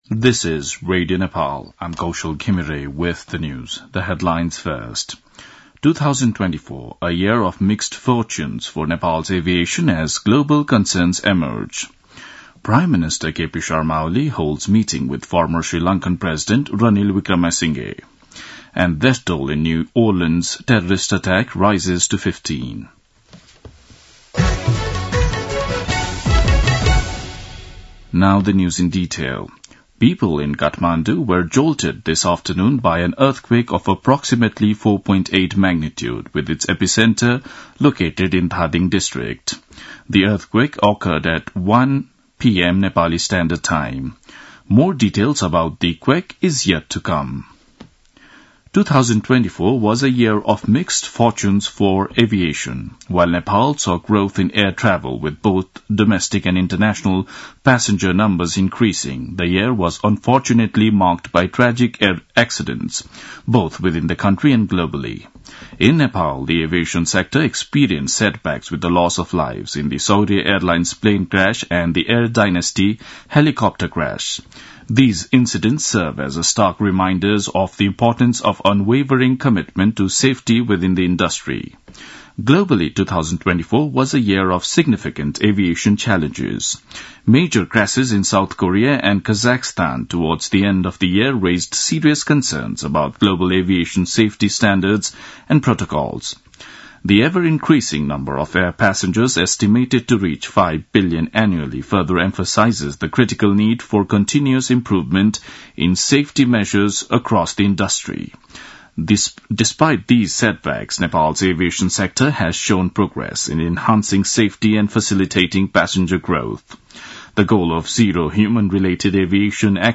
दिउँसो २ बजेको अङ्ग्रेजी समाचार : १९ पुष , २०८१
2-pm-english-news-1-1.mp3